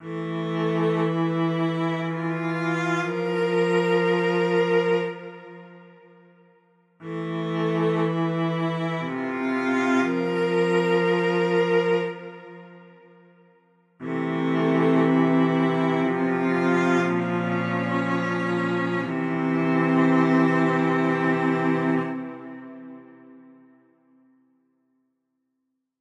ascending bowed cello cinematic complaining dragging duet film sound effect free sound royalty free Movies & TV